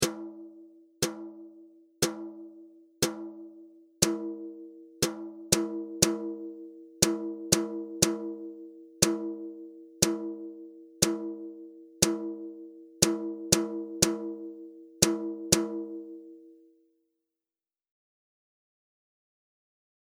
Ear Training Exercise 2: Rhythm Quiz
Each example will start out with four quarter notes, followed by a rhythm using 8th notes, quarter notes, quarter rests, and eighth rests.